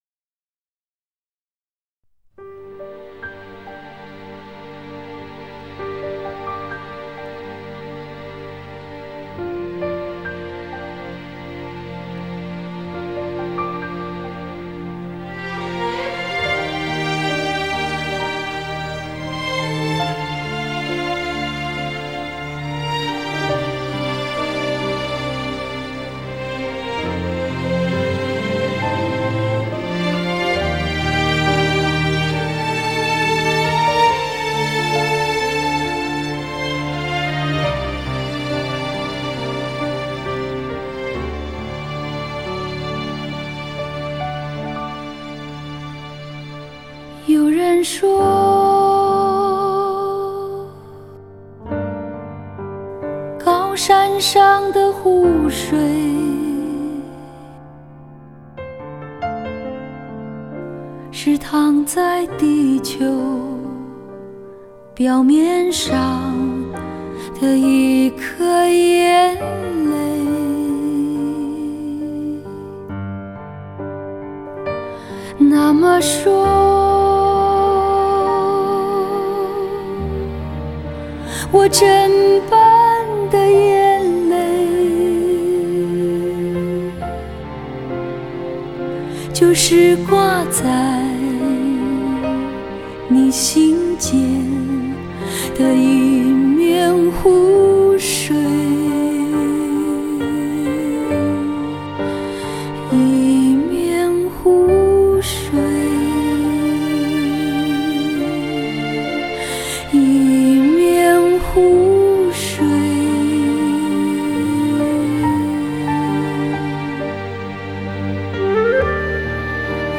深厚的演唱功底 充满情感的声音媚力 极度磁性的嗓音特质